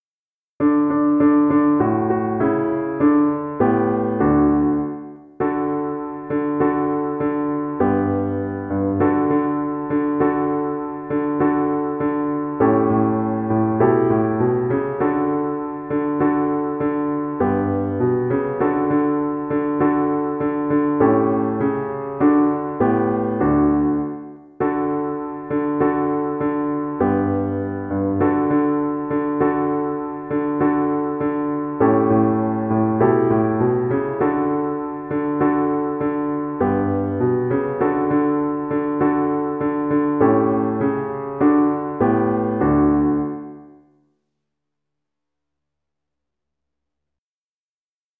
Free Sheet music for Choir (3 Voices)
4/4 (View more 4/4 Music)
Choir  (View more Beginners Choir Music)